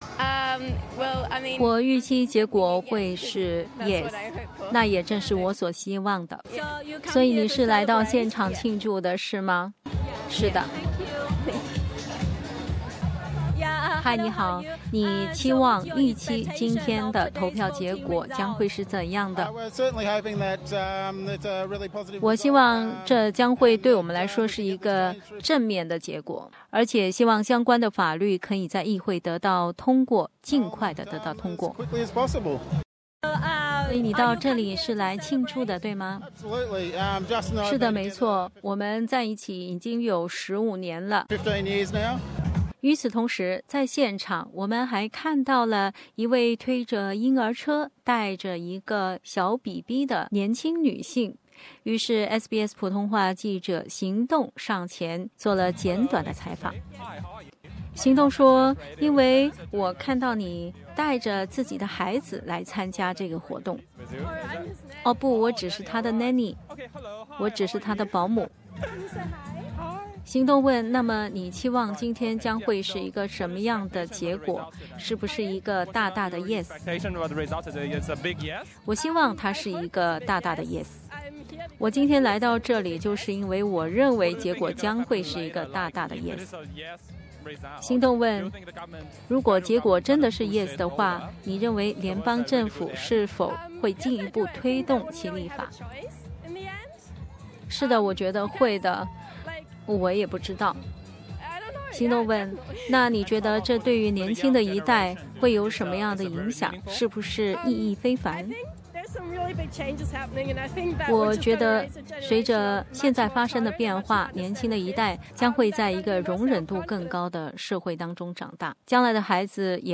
Live report from Prince Alfred Park